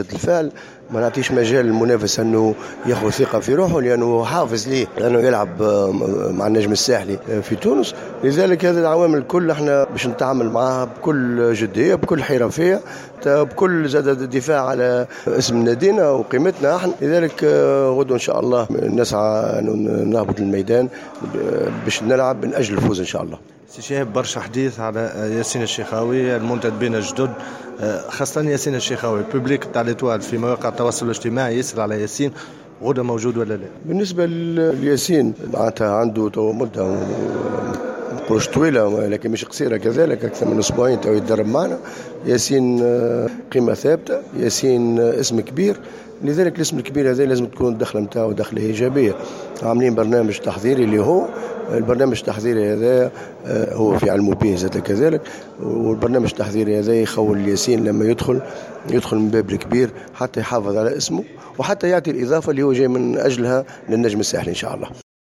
ندوة صحفية بقاعة الندوات الصحفية بالملعب الأولمبي بسوسة للحديث حول مواجهة الرمثا الأردني في إطار الجولة الأولى من منافسات كأس العرب للأندية.